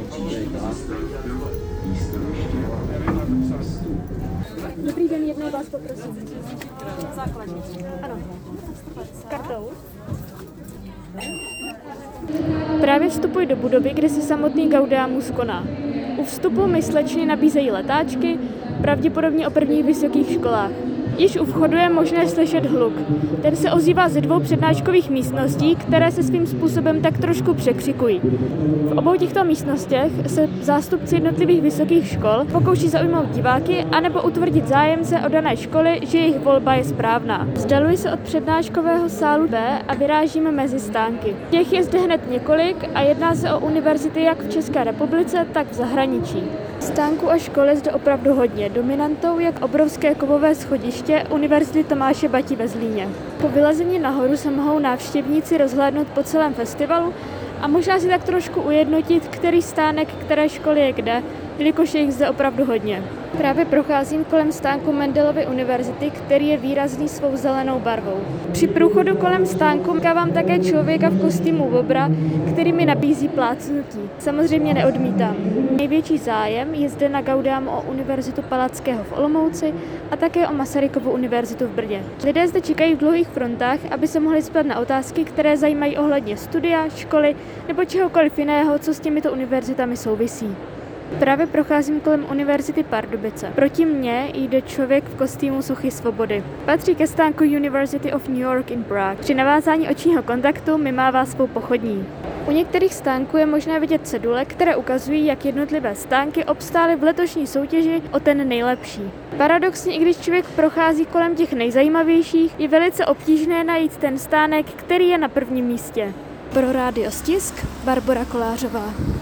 reportáž